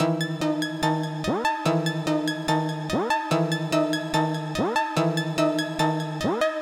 陷阱旋律 G 145 bpm
描述：我做了一个快速的小旋律。
标签： 145 bpm Trap Loops Synth Loops 1.11 MB wav Key : G
声道立体声